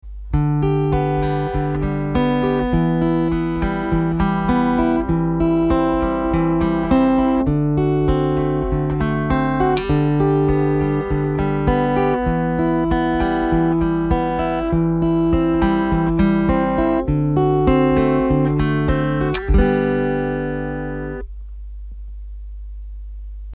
Next try playing the following exercise using the 4 chords of G E minor C and D7.
This exercise has a 50s pop ballad style similar to a Sam Cooke or Richie Valens song.
Fingerpicking 50s Style Arpeggios
The aim of all these fingerpicking exercises in this guitar lesson is to create a smooth flowing sound with all your plucking fingers playing at the same volume and in time.
fingerpicking_50s.mp3